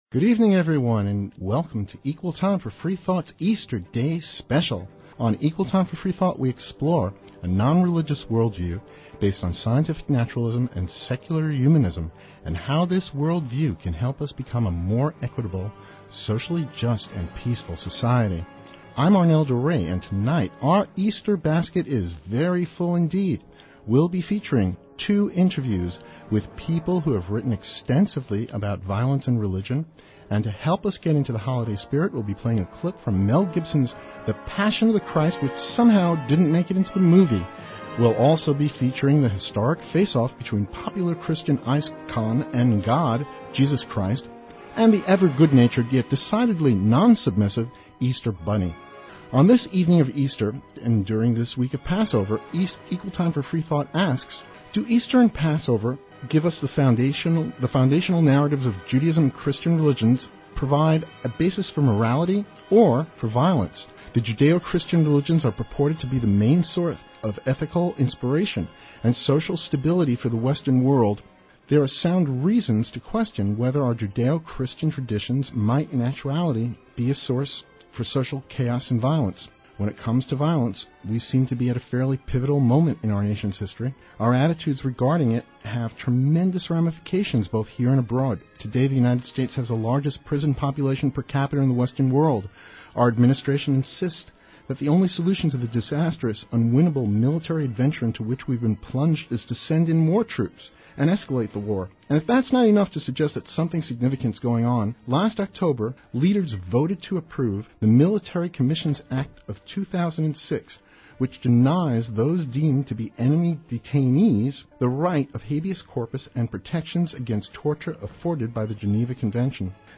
Interview: Questioning Judeo-Christian Morality